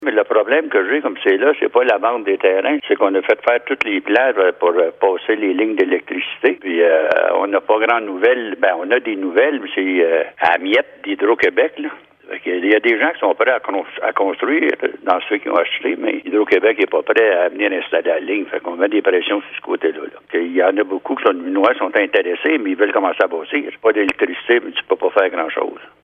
Le maire de Messines, Ronald Cross, précise que s’il était possible de bâtir dès l’achat du terrain, la demande serait encore plus importante :